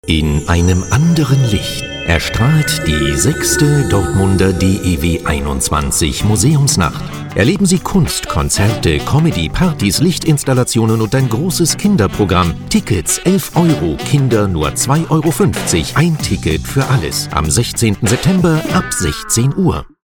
Sprecher deutsch.
Sprechprobe: Werbung (Muttersprache):
german voice over artist